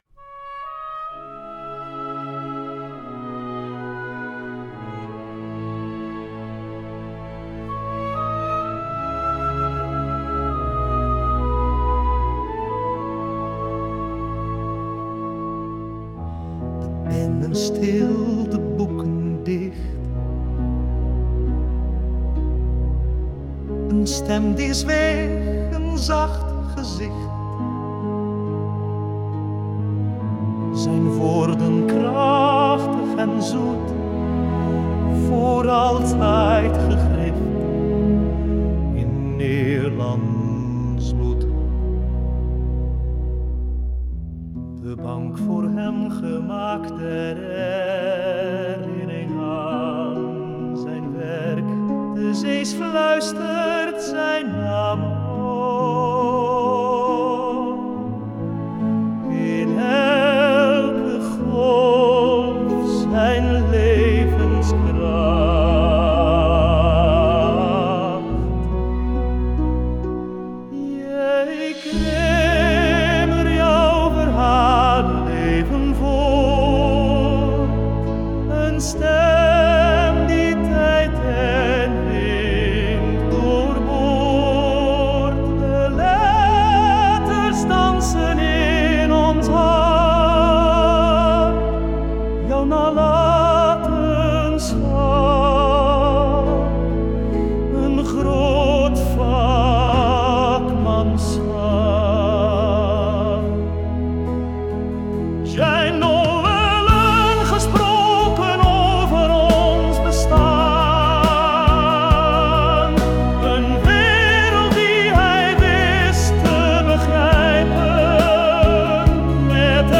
In 1880 stierf hij op 52 jarige leeftijd. Een eigentijdse gezongen ode aan J.J. Cremer: